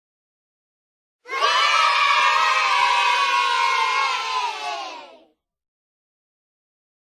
Children-Yay-Sound-Effect.m4a
LCxc2GD40iw_Children-Yay-Sound-Effect.m4a